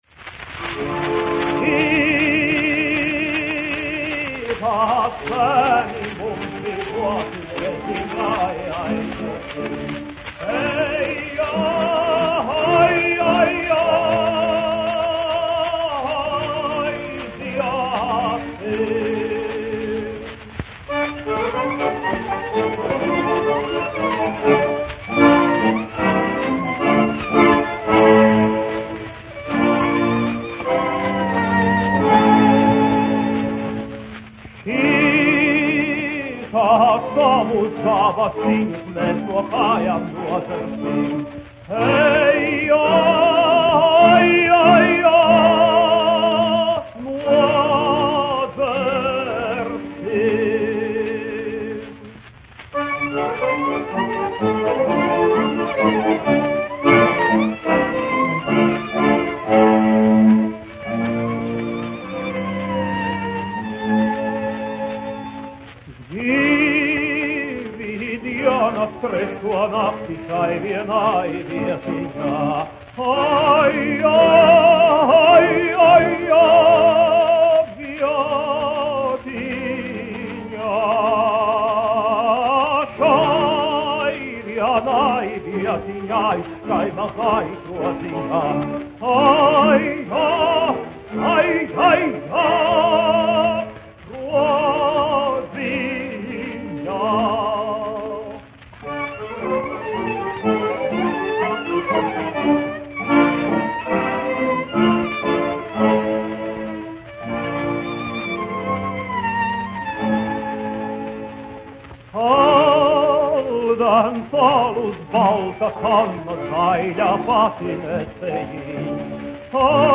Šitā zēni mums dzīvoti : tautas dziesma
Mariss Vētra, 1901-1965, dziedātājs
1 skpl. : analogs, 78 apgr/min, mono ; 25 cm
Latviešu tautasdziesmas
Latvijas vēsturiskie šellaka skaņuplašu ieraksti (Kolekcija)